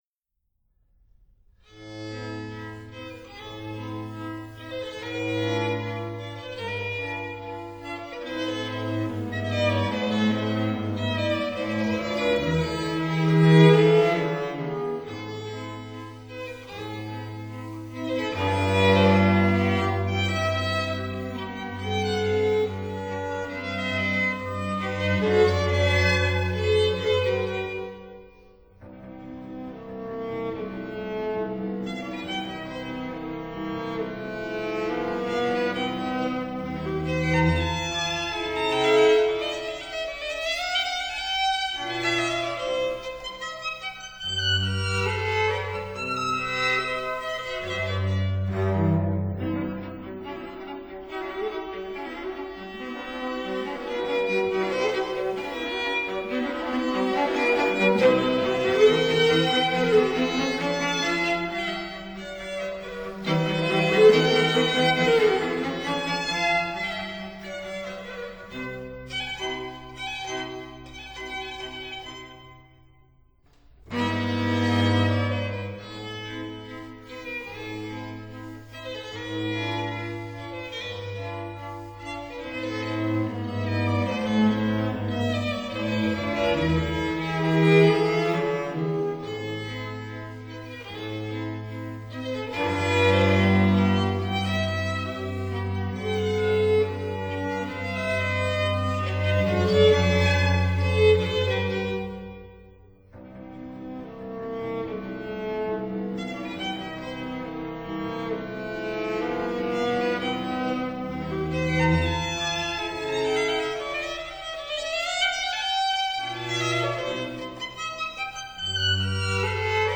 String Quartet in A minor, Op. 2 No. 4 (L.153)
(Period Instruments)